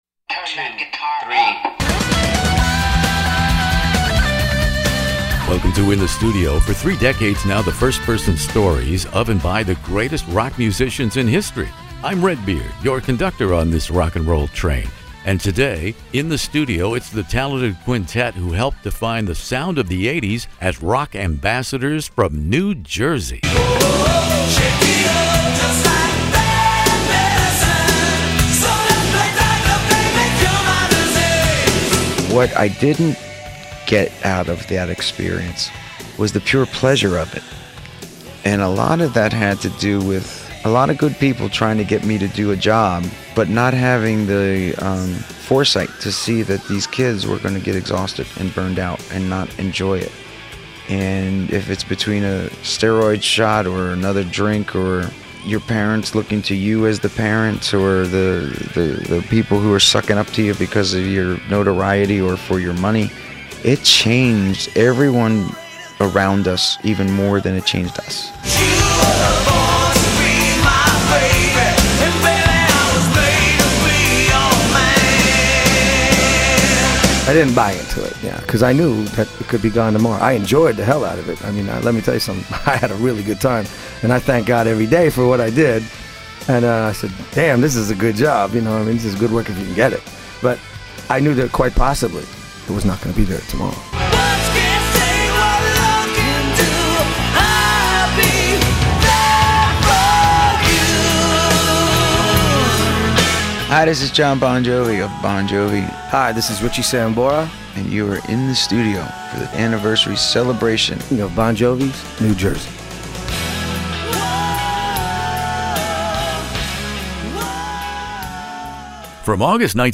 One of the world's largest classic rock interview archives, from ACDC to ZZ Top, by award-winning radio personality Redbeard.
Jersey boys Jon Bon Jovi and Richie Sambora join me here In the Studio for a particularly revealing look behind the curtain at the price of a permanent address in the Rock and Roll Hall of Fame on New Jersey‘s thirty-fifth anniversary.